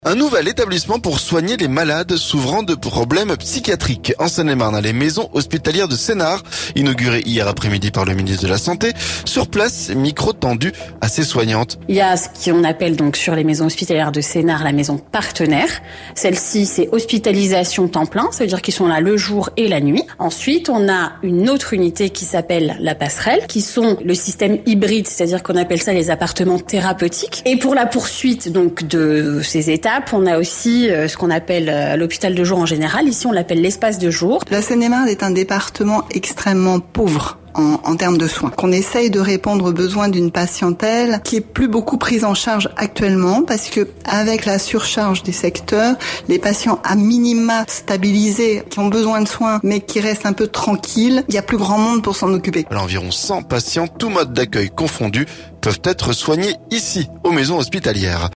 Sur place, micro tendu à des soignantes.